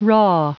Prononciation du mot raw en anglais (fichier audio)
Prononciation du mot : raw